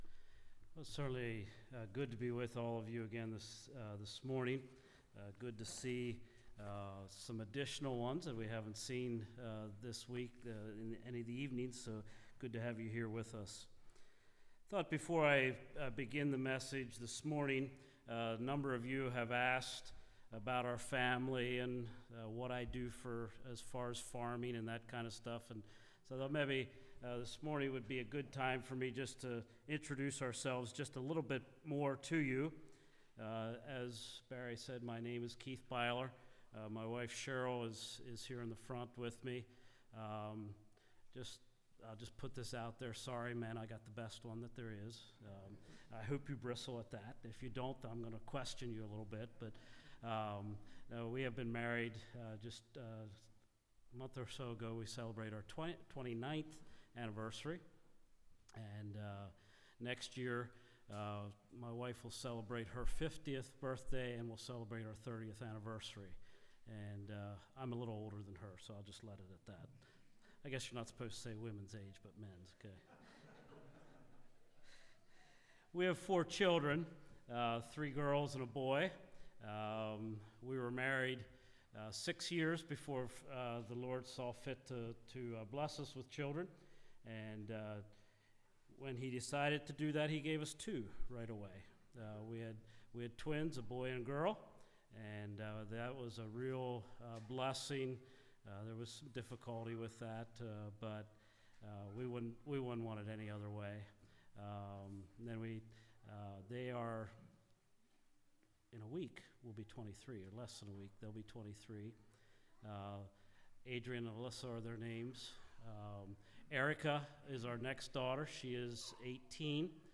Service Type: Revivals